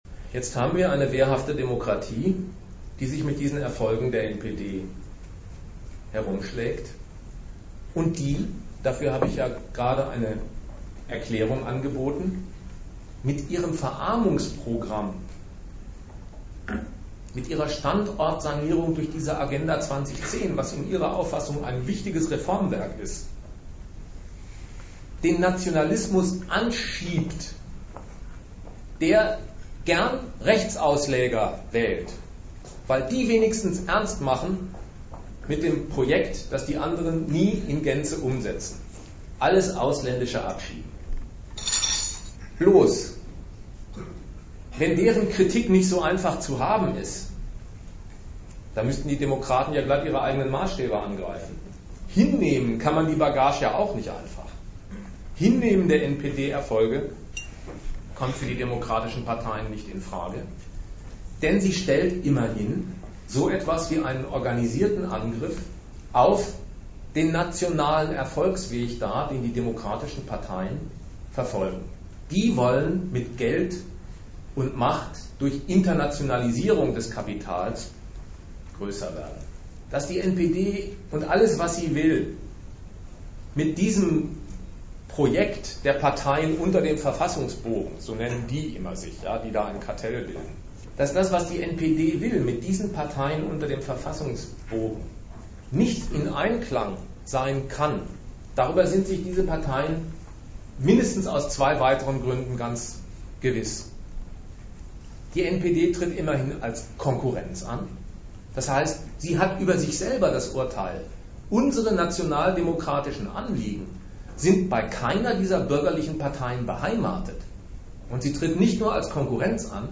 Datum 13.03.2005 Ort Bremen Themenbereich Rechtsstaat und Demokratie Veranstalter Argudiss Dozent Gastreferenten der Zeitschrift GegenStandpunkt Die NPD hat einigen Erfolg.